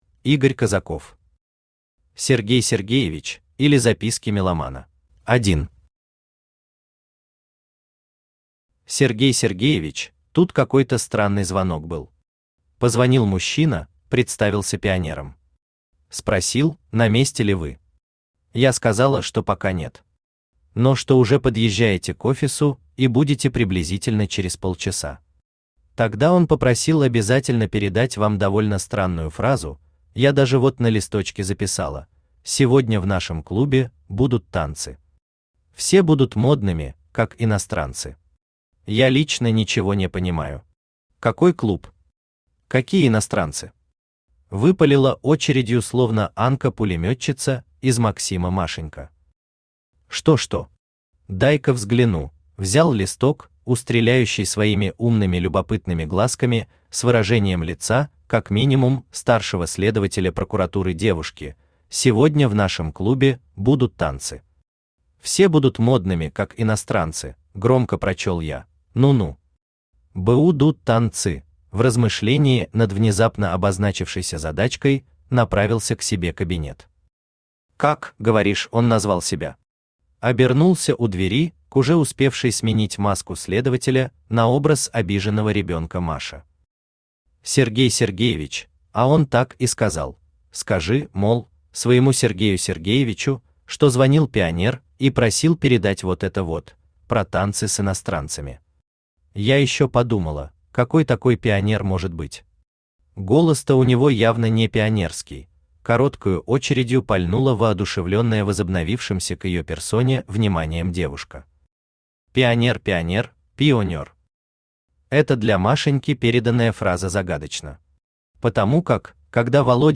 Aудиокнига Сергей Сергеевич, или Записки меломана Автор Игорь Козаков Читает аудиокнигу Авточтец ЛитРес.